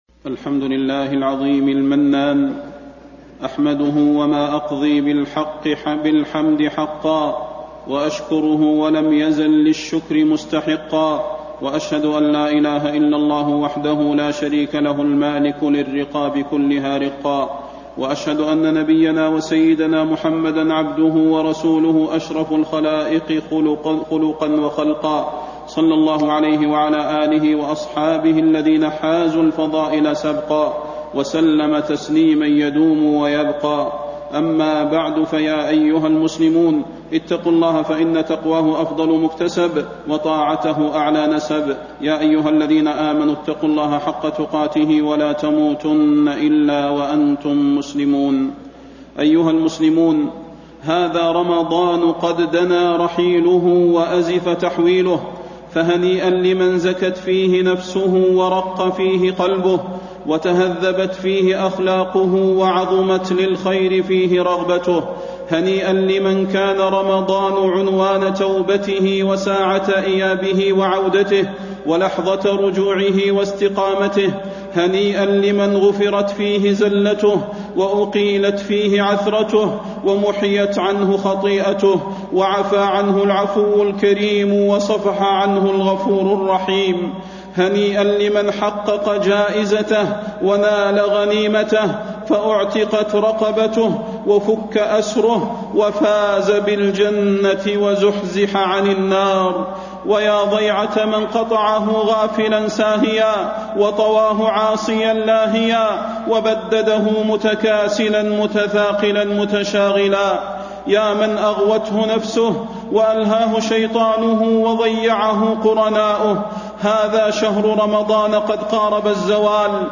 فضيلة الشيخ د. صلاح بن محمد البدير
تاريخ النشر ٢٧ رمضان ١٤٣٥ هـ المكان: المسجد النبوي الشيخ: فضيلة الشيخ د. صلاح بن محمد البدير فضيلة الشيخ د. صلاح بن محمد البدير زكاة الفطر وصلاة العيد